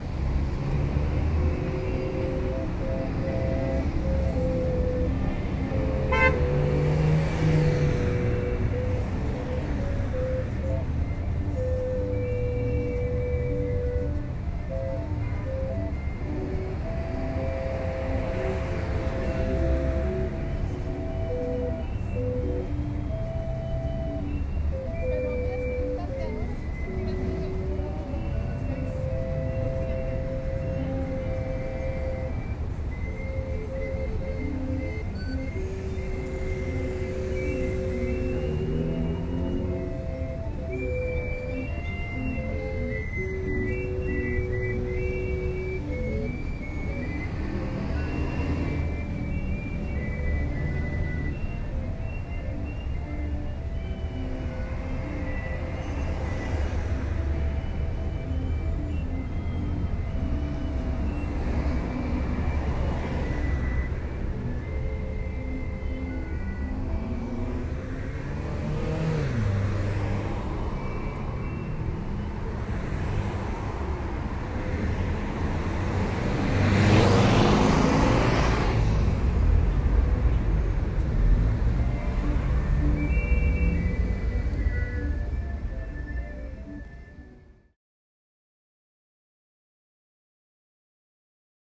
Recorded at a busy intersection near the entrance of the Viveros de Coyoacán, I was told the unusual tuning of the hand-cranked reed organ results from a lack of maintenance by the city who issue the instruments to their registered players.
organ_mexico_city.ogg